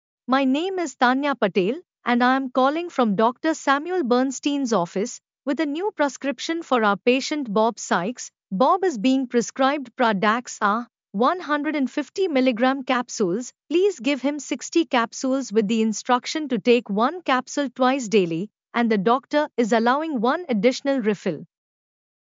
Practice Taking Verbal Prescriptions